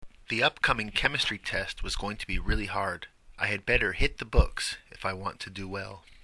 英語ネイティブの発音は以下のリンクをクリックしてください。